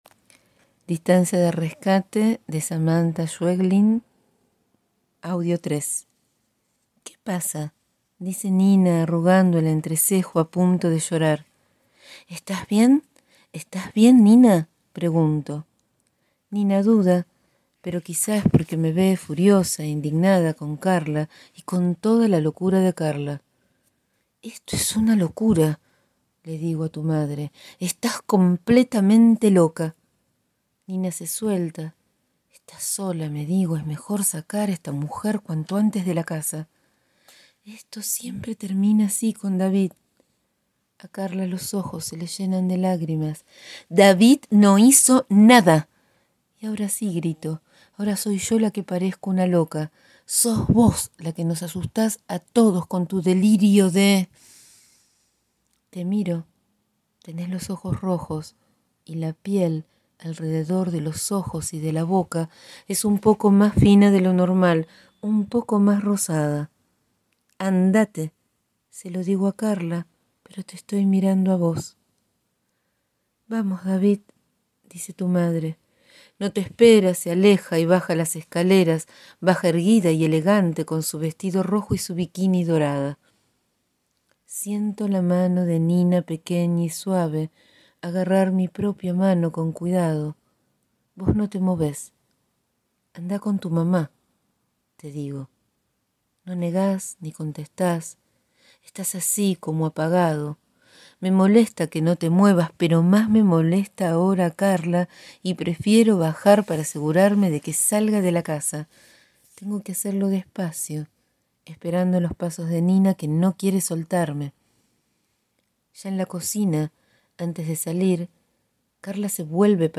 Continuamos con la lectura de esta novela. Este diálogo al borde de la vida, entre la conciencia y lo onírico, en la búsqueda de una causa actuada cotidianamente.